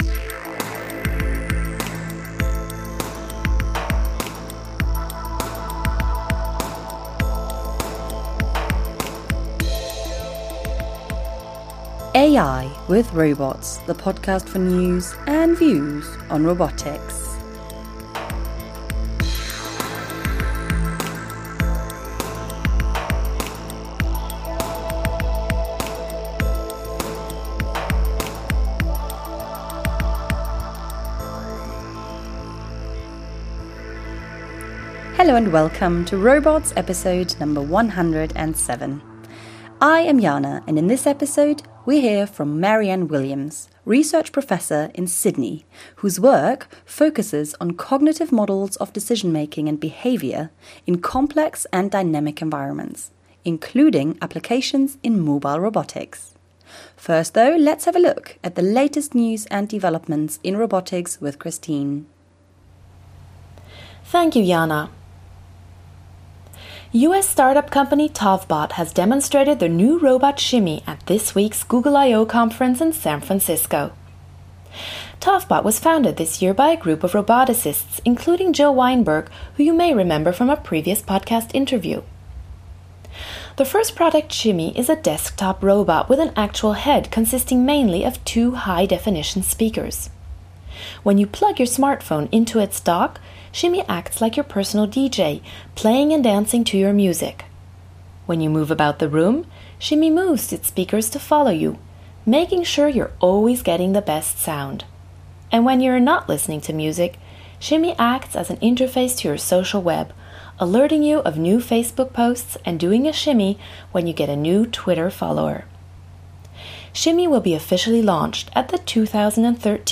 In this interview, she talks about her work, her involvement with the International Conference in Social Robotics and the PR2 robot.